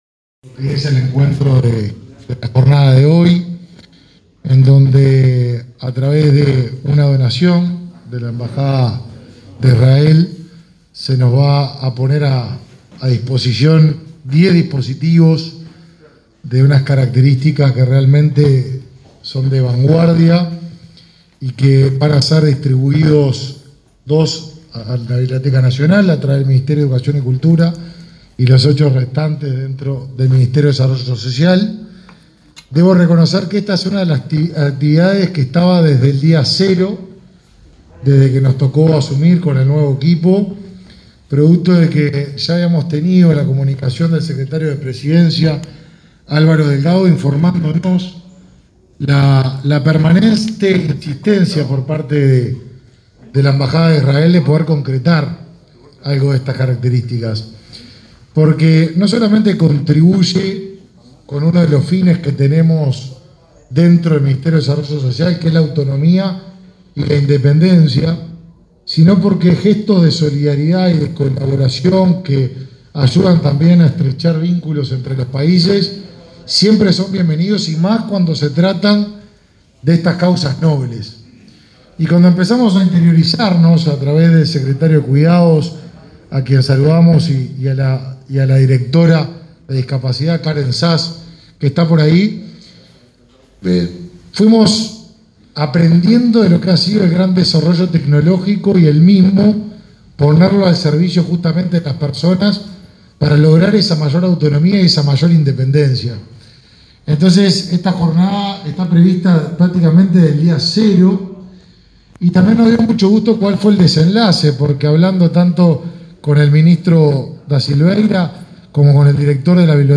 Conferencia de prensa del secretario de Presidencia, Álvaro Delgado, y de los ministros Martín Lema y Pablo da Silveira
Este lunes 5, en la sede del Mides, el secretario de Presidencia, Álvaro Delgado, los ministros del Mides, Martín Lema, y del MEC, Pablo da Silveira,